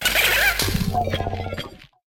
Cri de Chélicères-de-Fer